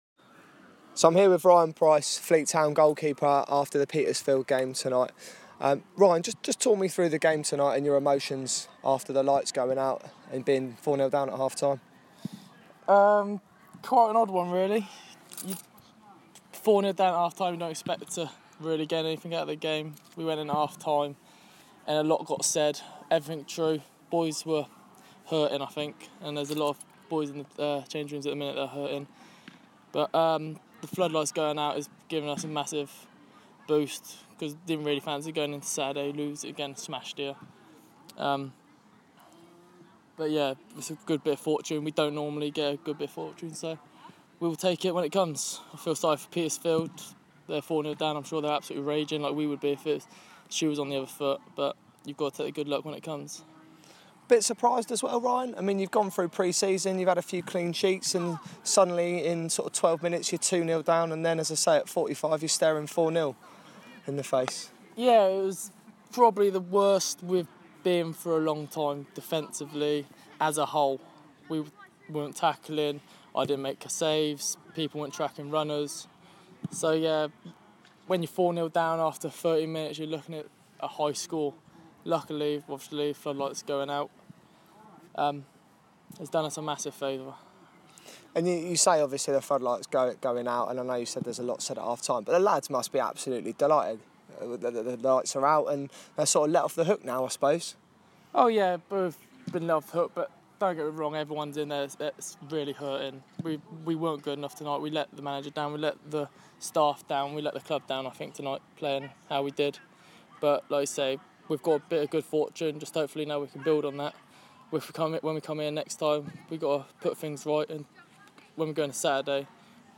Player interview